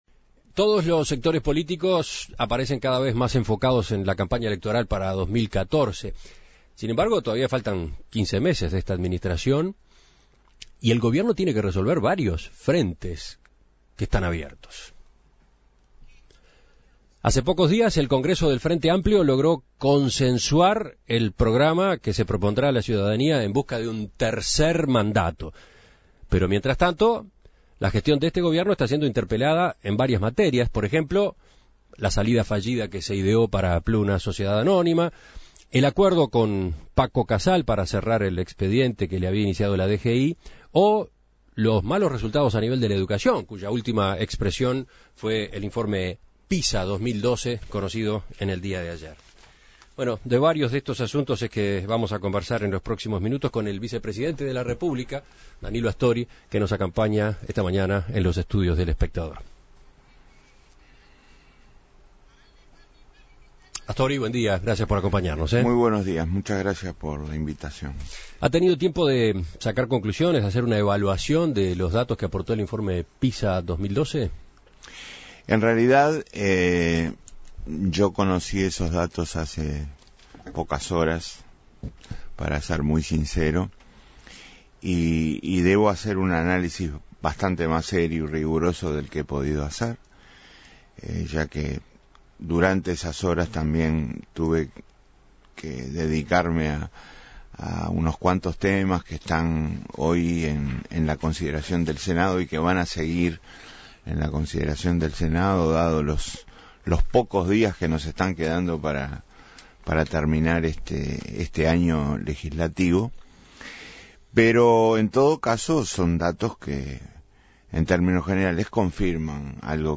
En Perspectiva dialogó con el vicepresidente Astori sobre este y otros temas, como educación, economía, el caso Pluna y el cierre del expediente de Casal.